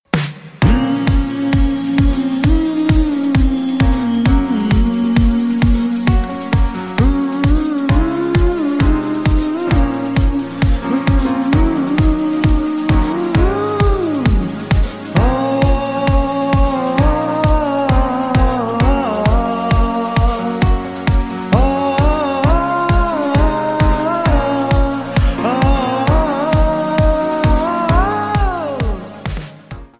Bbm
MPEG 1 Layer 3 (Stereo)
Backing track Karaoke
Pop, Disco, 1980s